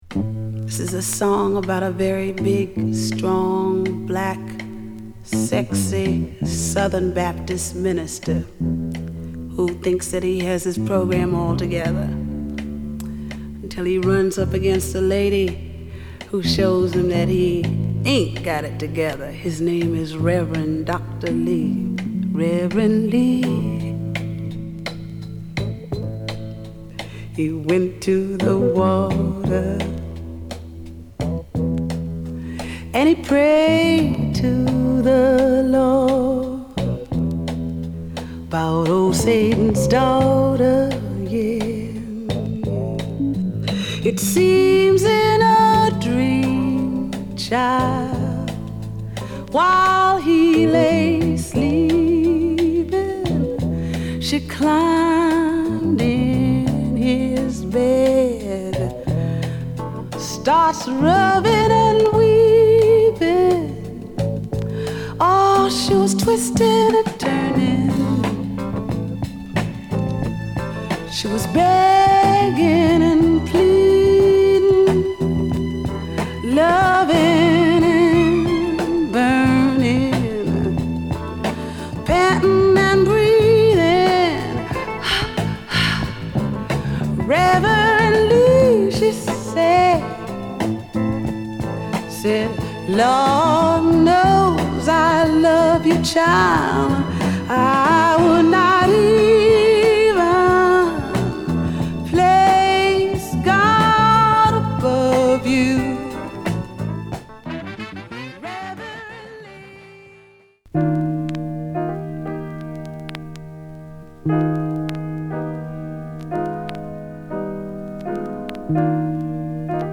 アルバム通して美しくメロウな世界です！